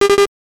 NOTIFICATION_8bit_05_mono.wav